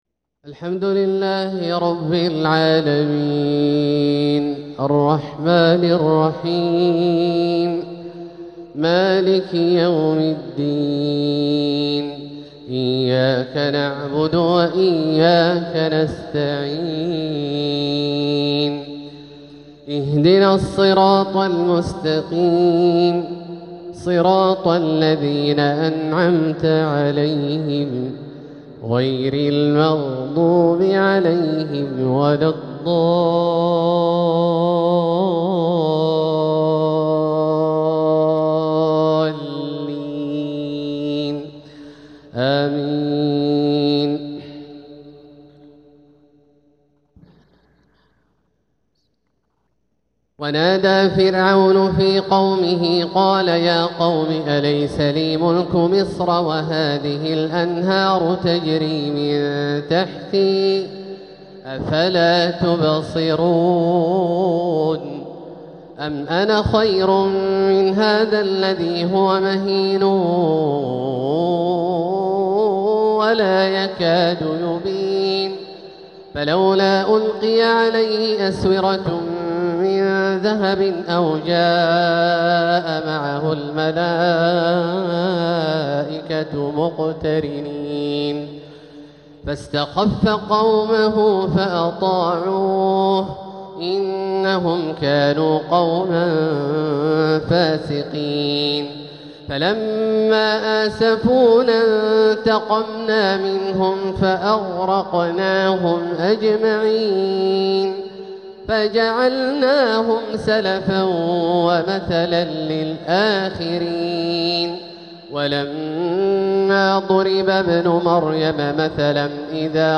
تلاوة من سورة الزخرف 51-73 | عشاء الإثنين 16 ربيع الأول 1447هـ > ١٤٤٧هـ > الفروض - تلاوات عبدالله الجهني